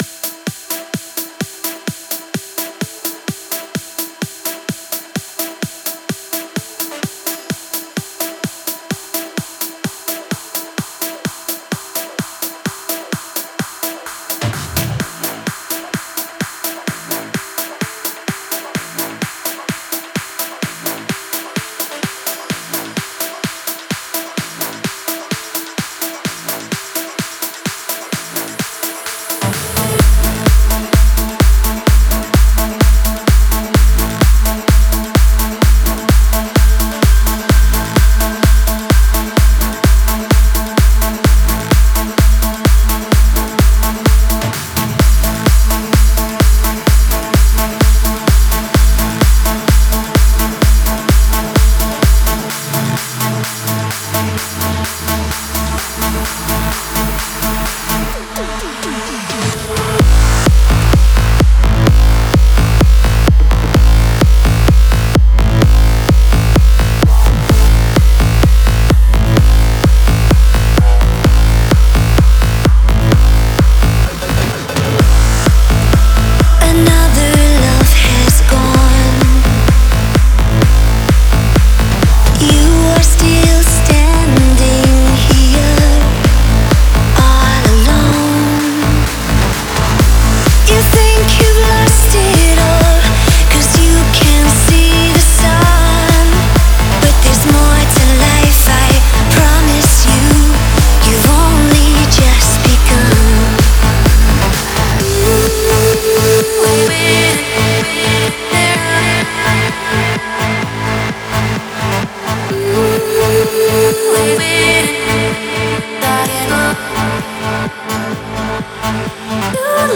(Original Mix)
Стиль: Progressive Trance / Vocal Trance